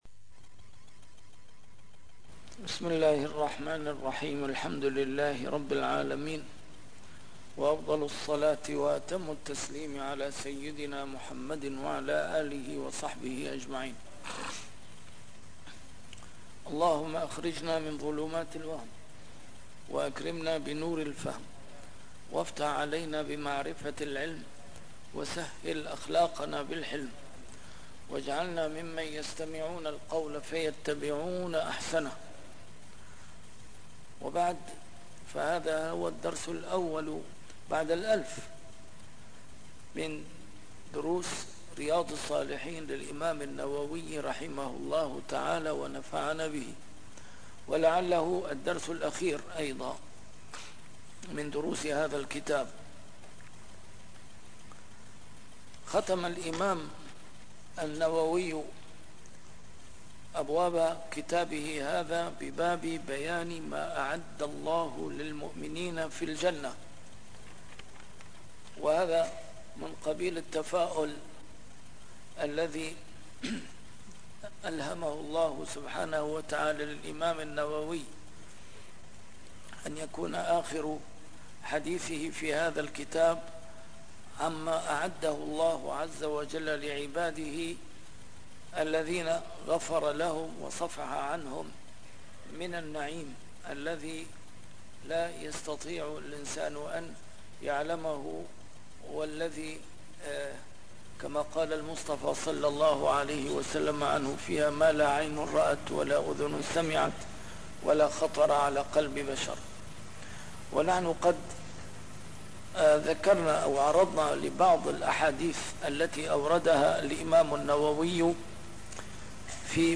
A MARTYR SCHOLAR: IMAM MUHAMMAD SAEED RAMADAN AL-BOUTI - الدروس العلمية - شرح كتاب رياض الصالحين - 1001- شرح رياض الصالحين: باب بيان ما أعد الله للمؤمنين في الجنة